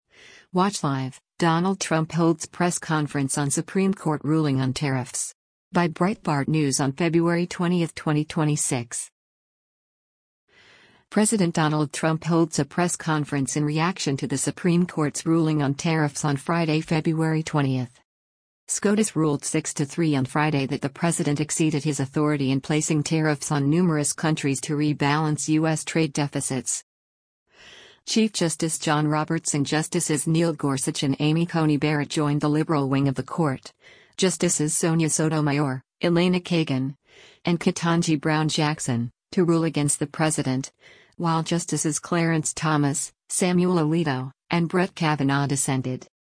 President Donald Trump holds a press conference in reaction to the Supreme Court’s ruling on tariffs on Friday, February 20.